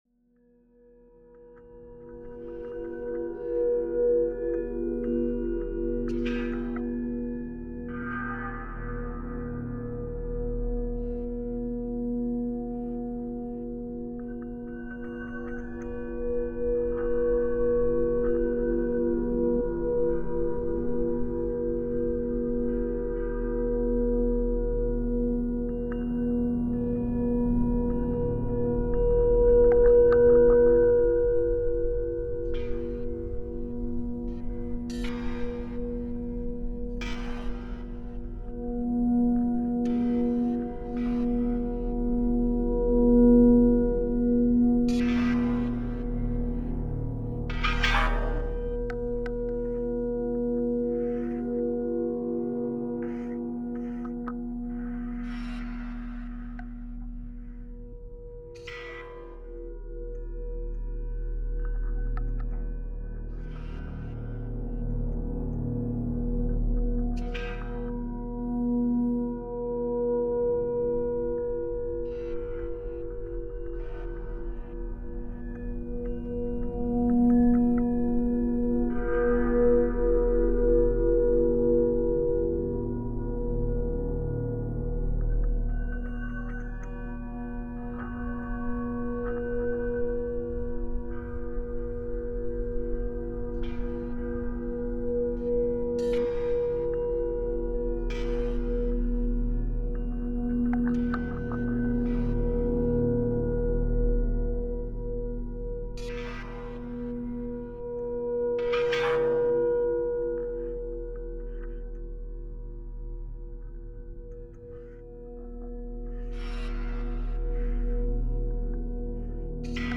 Extraits de la bande son de la performance de danse